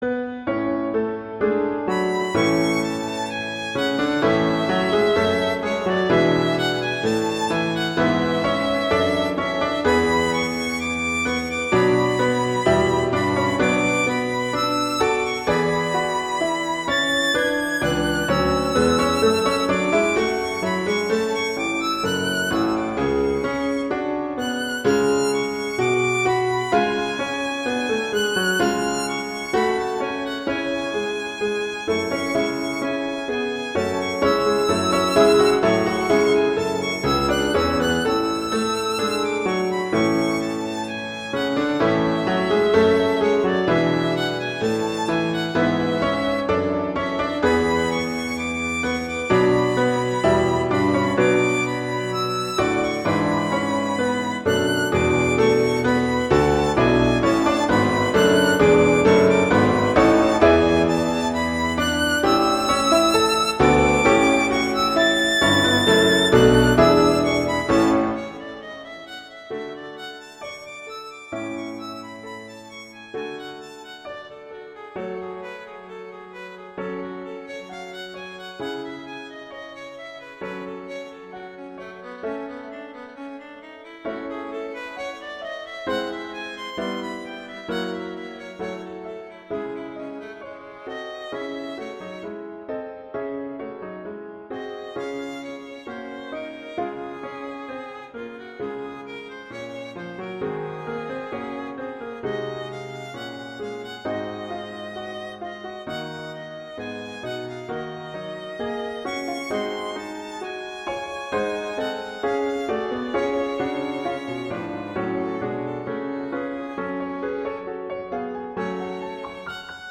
violin and piano
classical, french
♩=52-150 BPM (real metronome 52-152 BPM)